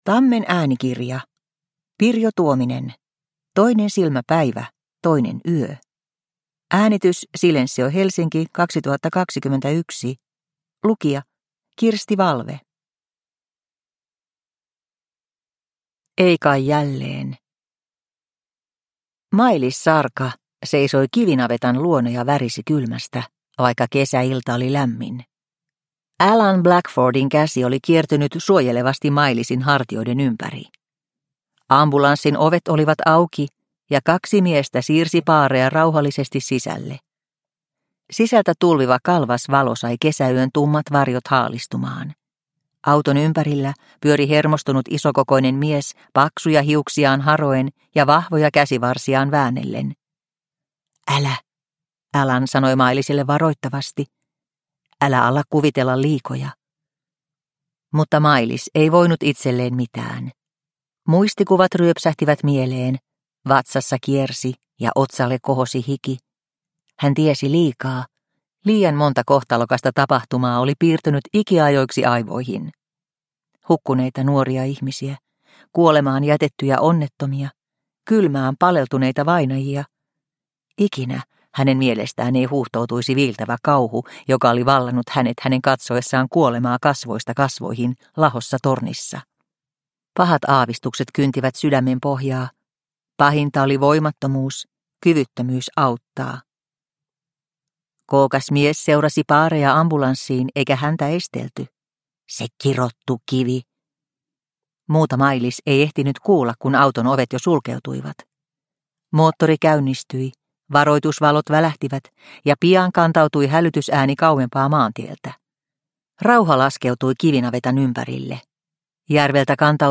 Toinen silmä päivä, toinen yö – Ljudbok – Laddas ner